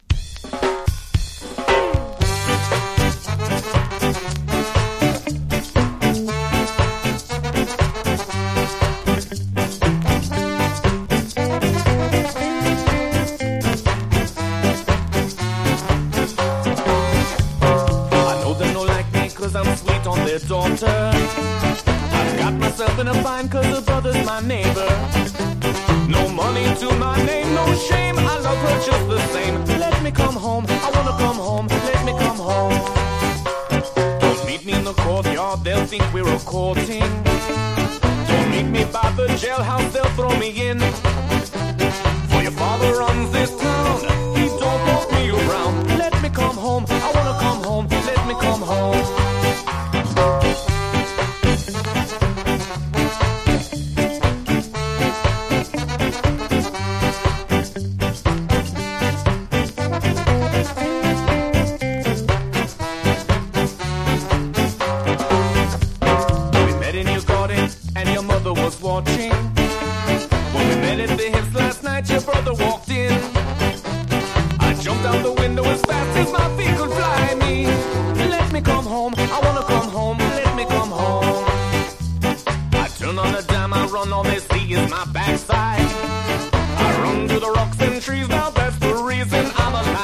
B面の古きよきオールディーズ・ナンバー風スカ･チューンもオススメ！